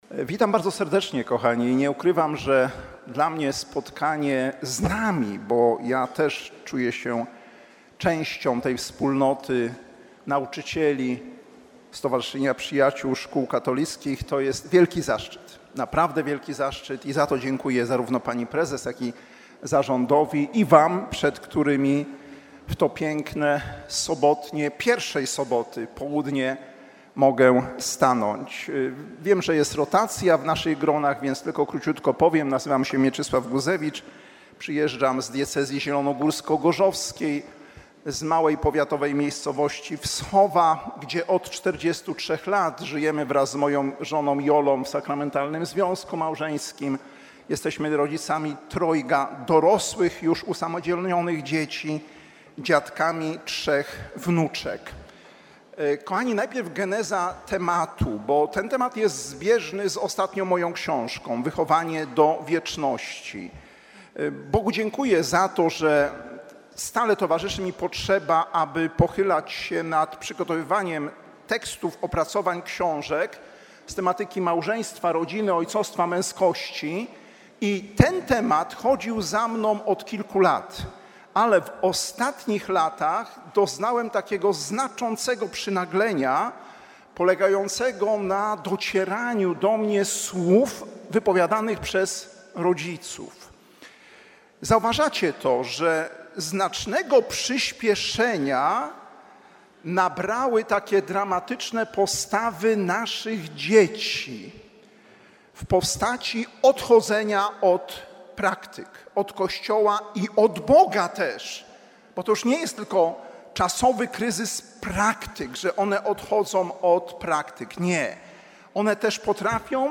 Nagranie z Radia Jasna Góra